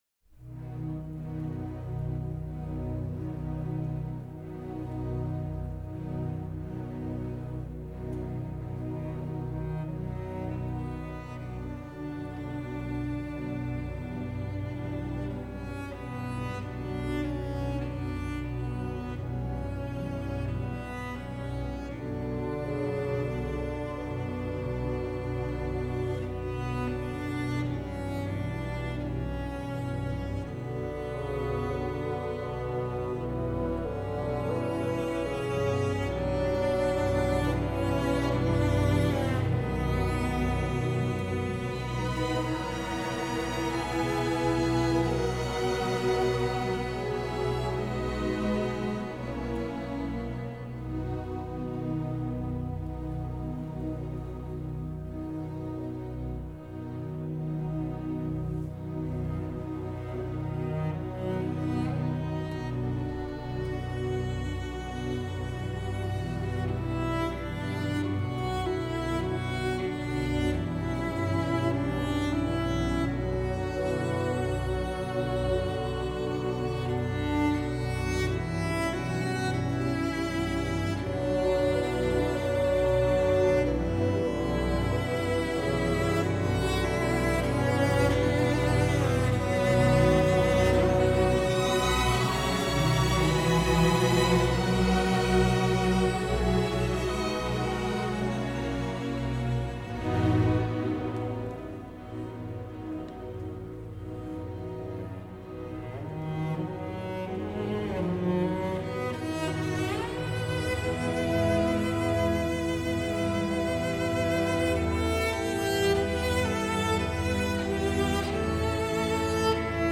1. The shifts from major to minor.
2. The oboe echoing the cello.
Tags1940s 1948 classical Europe-Asia orchestra